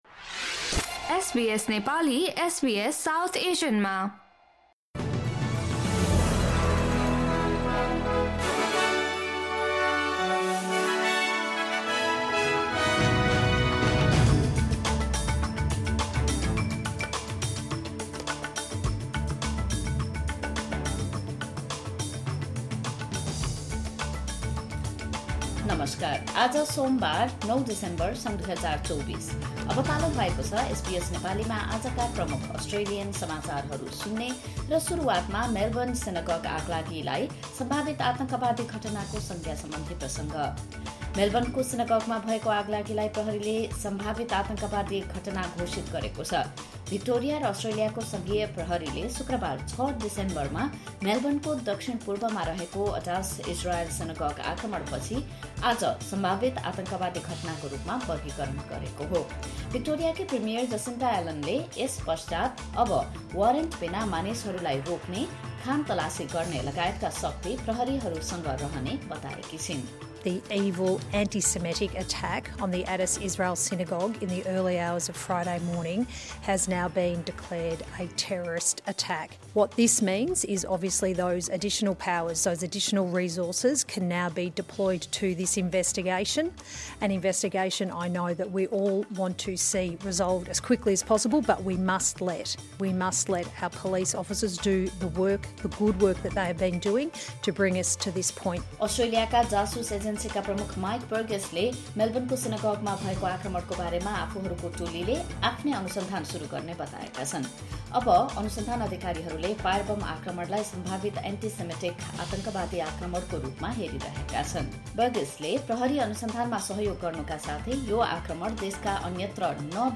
Listen to the latest top news from Australia in Nepali.